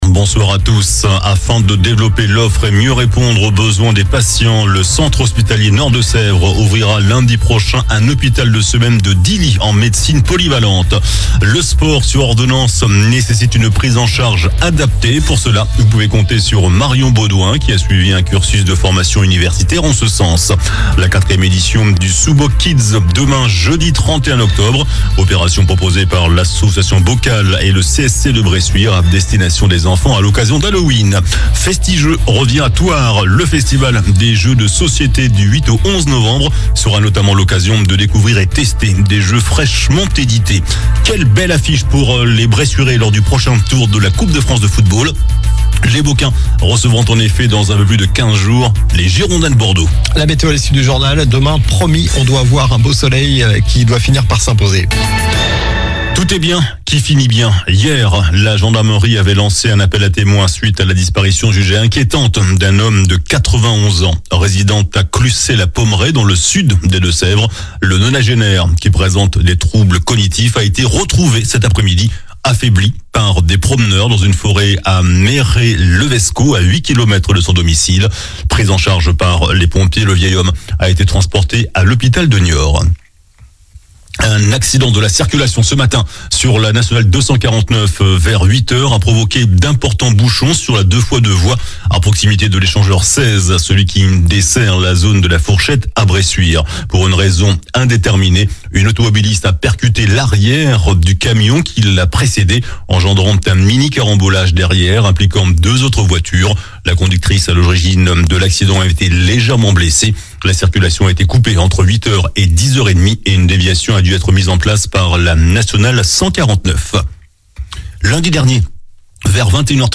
JOURNAL DU MERCREDI 30 OCTOBRE ( SOIR )